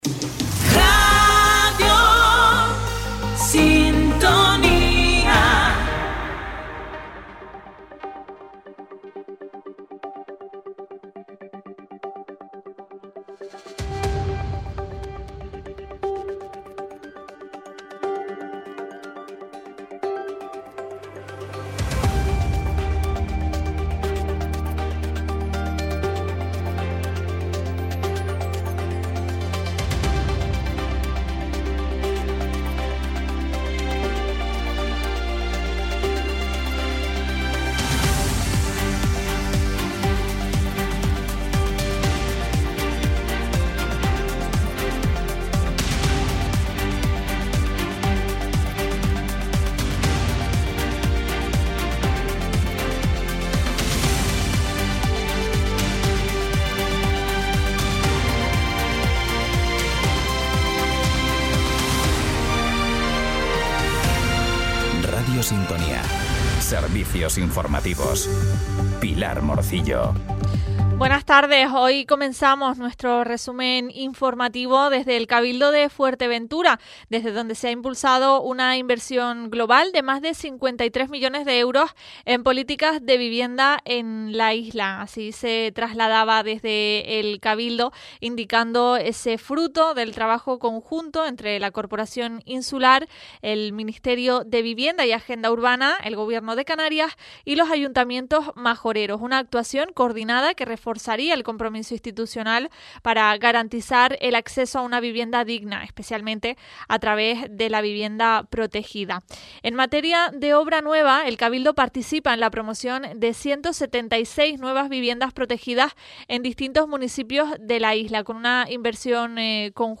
Cada día, desde Radio Sintonía, puedes seguir toda la información local y regional en nuestro espacio informativo. En él te contamos, en directo, las noticias más importantes de la jornada, a partir de las 13:15h.